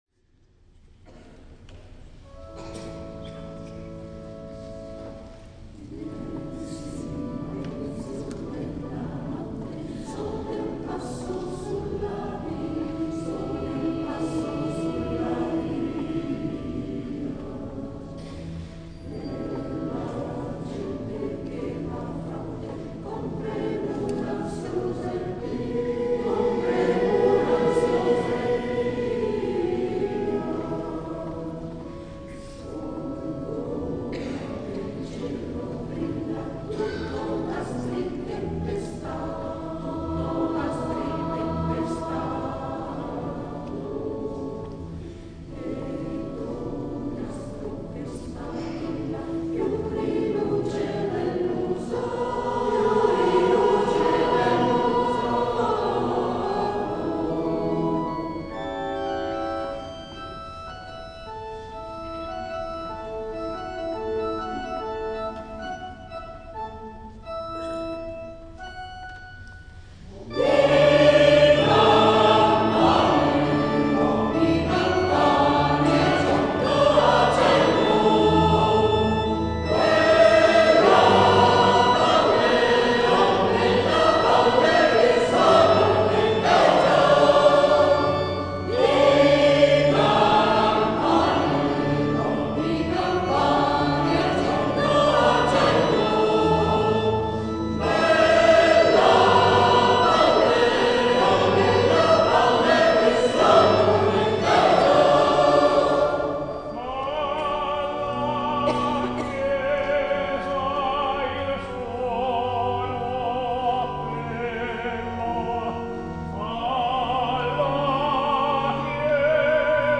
S. Gaudenzio church choir Gambolo' (PV) Italy
19 Dicembre - Concerto di Natale
La registrazione audio del Concerto (MP3)